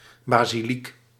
Ääntäminen
Synonyymit église mosquée temple chapelle cathédrale synagogue basilica Ääntäminen France: IPA: [ba.zi.lik] Haettu sana löytyi näillä lähdekielillä: ranska Käännös Ääninäyte Substantiivit 1. basiliek {f} Suku: f .